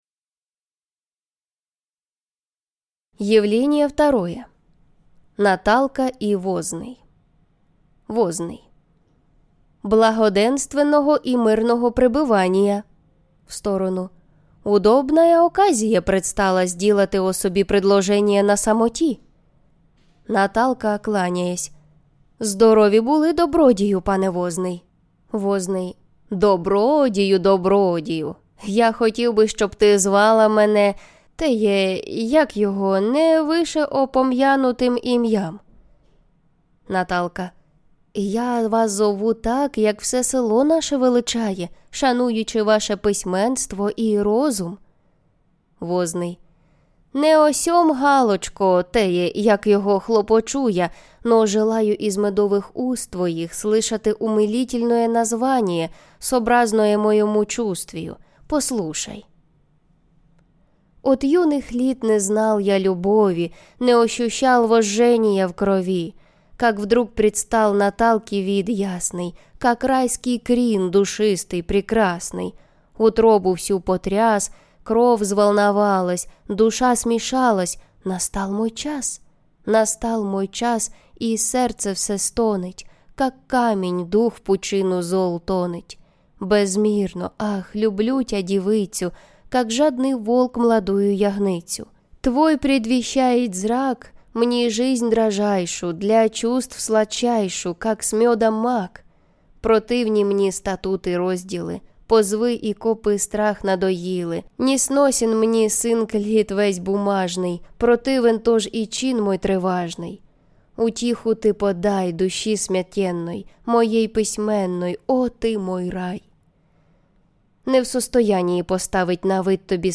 Наталка - Полтавка: Книги українською, українська література - Іван Котляревський - audiobook